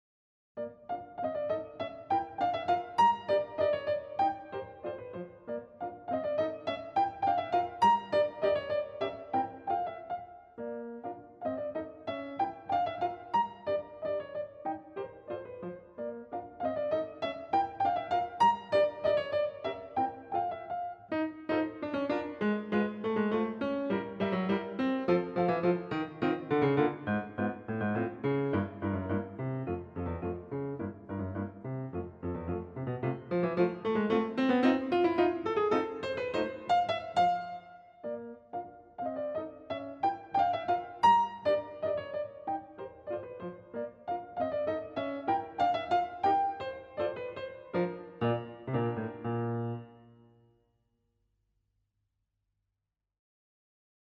фортепианная версия